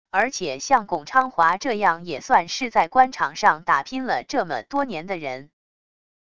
而且像巩昌华这样也算是在官场上打拼了这么多年的人wav音频生成系统WAV Audio Player